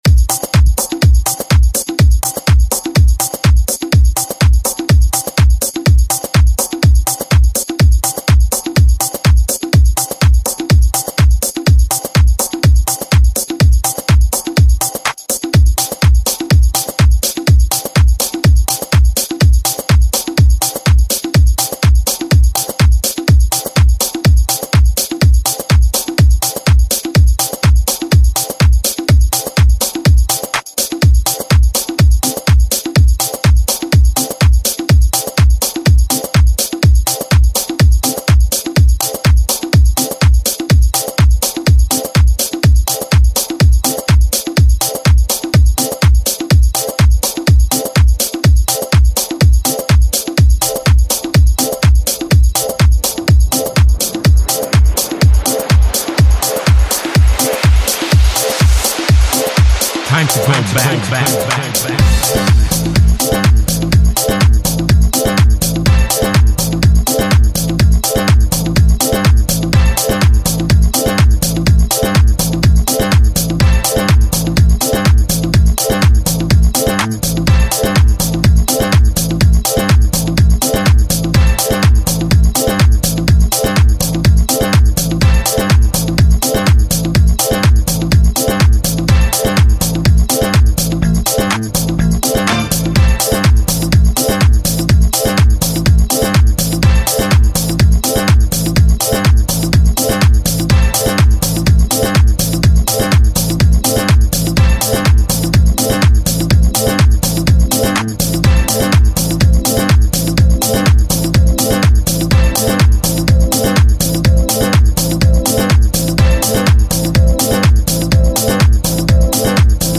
New York Disco Mix
who serve’s us with three deep and funky jams